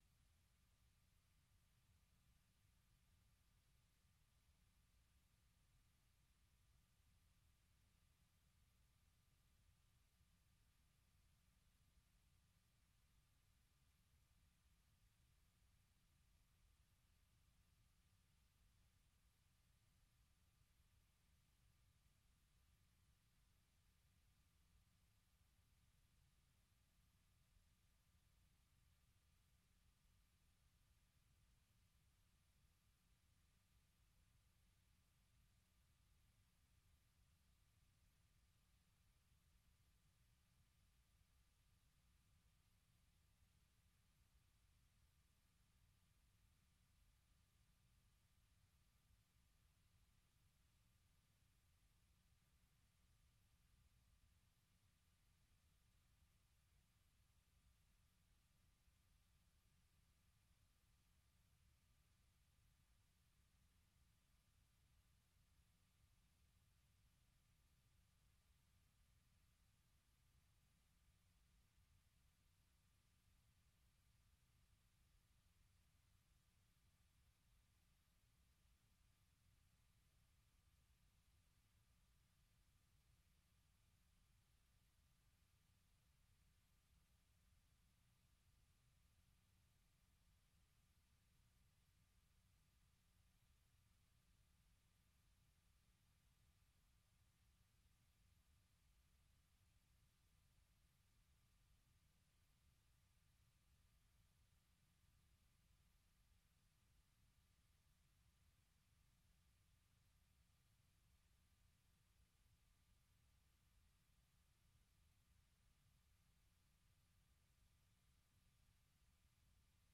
Kallabi wani sabon shiri ne kacokan kan mata, daga mata, a bakin mata, wanda Sashen Hausa na Muryar Amurka ya kirkiro don maida hankali ga baki daya akan harakokin mata, musamman a kasashenmu na Afrika. Shirin na duba rawar mata da kalubalensu ne a fannoni daban-daban na rayuwa.